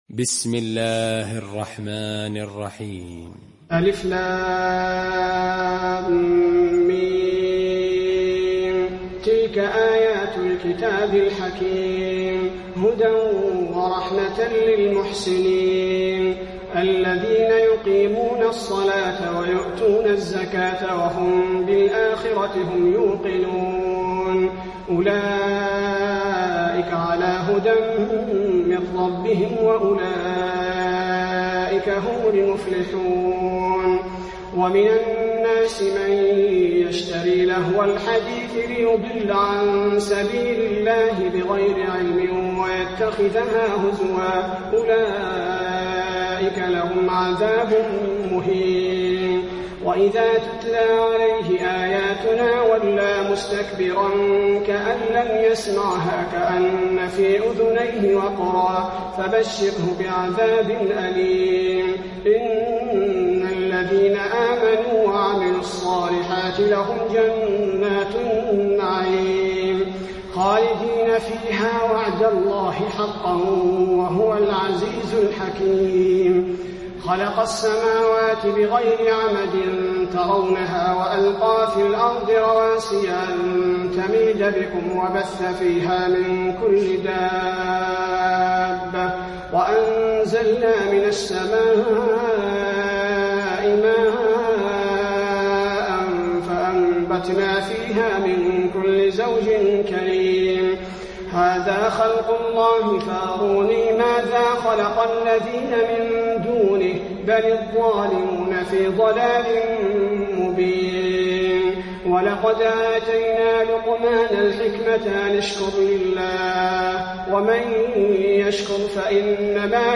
المكان: المسجد النبوي لقمان